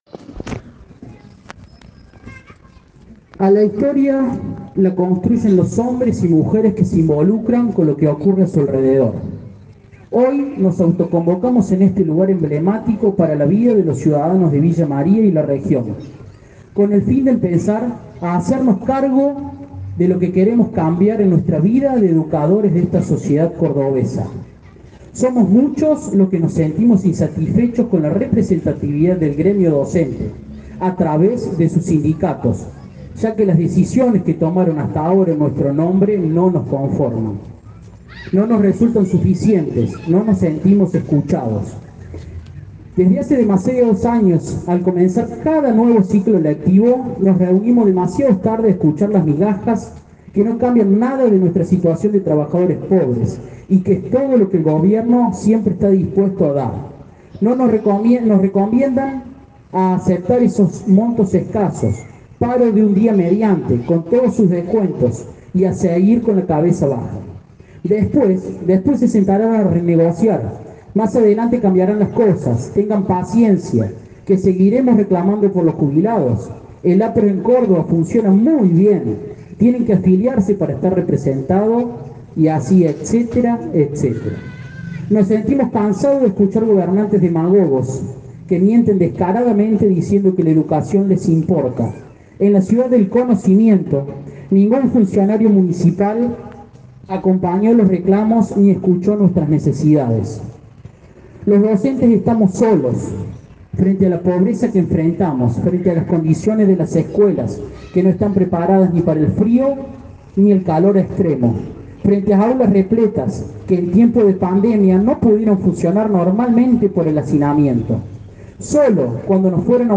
Unos 300 docentes y jubilados autoconvocados se concentraron este lunes frente al Centro Cívico de Villa María.